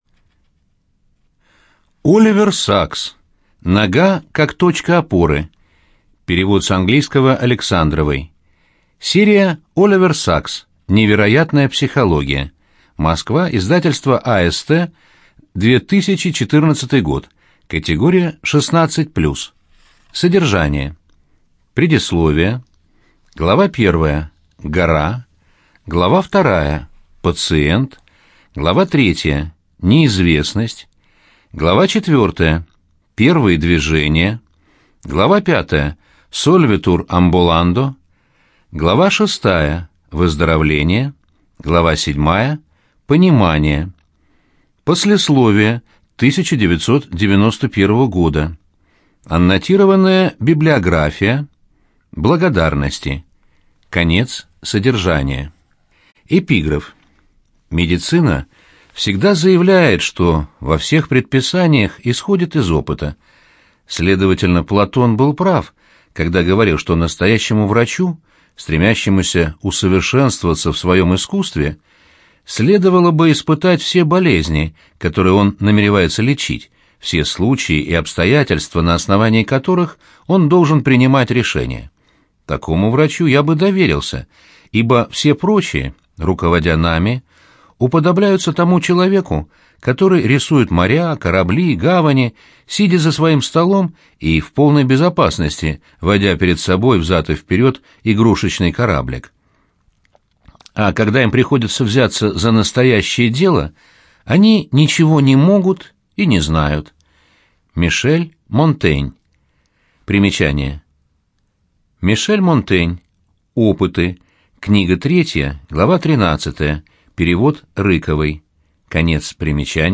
ЖанрНаука и образование, Научно-популярная литература, Биографии и мемуары, Медицина и здоровье, Психология
Студия звукозаписиЛогосвос